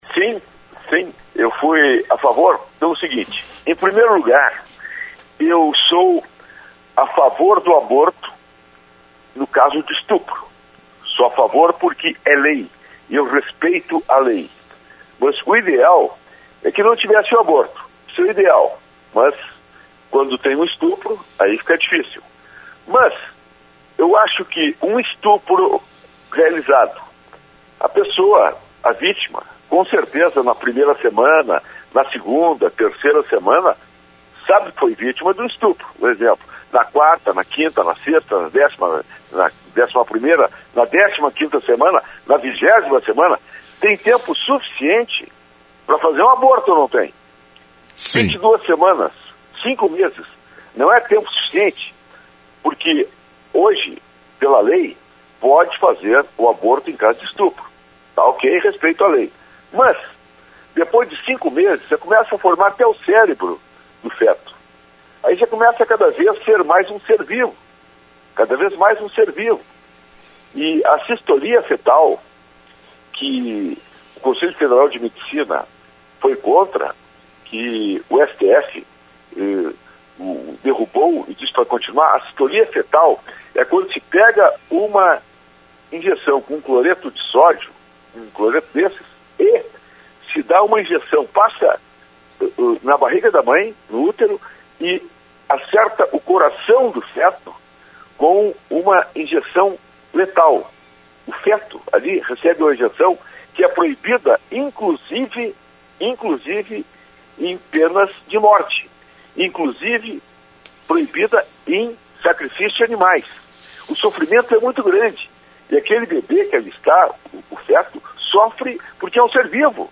Rádio Lagoa FM entrevistou deputado Bibo Nunes na manhã desta terça-feira. Procurou justificar sua posição.